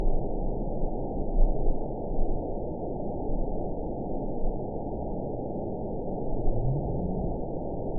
event 922365 date 12/30/24 time 03:46:10 GMT (11 months ago) score 9.28 location TSS-AB10 detected by nrw target species NRW annotations +NRW Spectrogram: Frequency (kHz) vs. Time (s) audio not available .wav